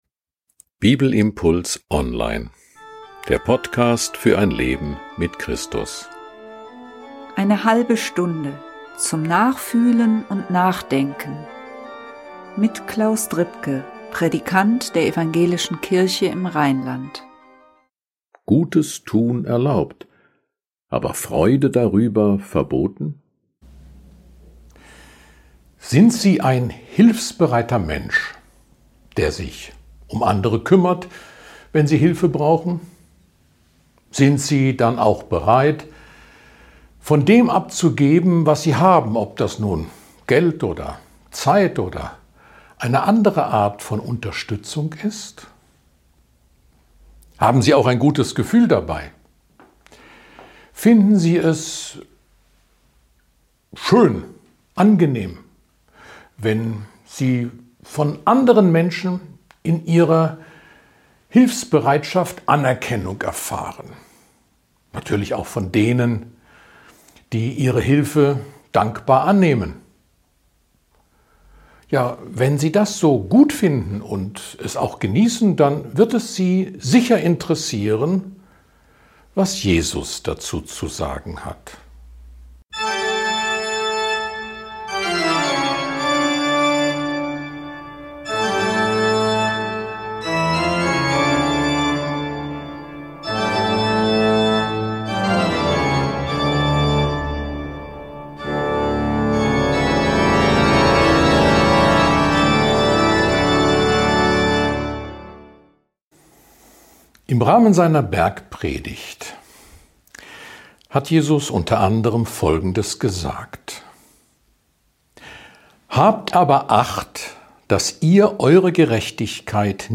Jesus hat auch darüber eine sehr genaue und ungewöhnliche Ansicht, die sicherlich nicht jedem schmeckt ... Ein Bibelimpuls zu Matthäus 6, 1-4